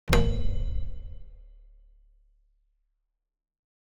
UI sound trad select 1.wav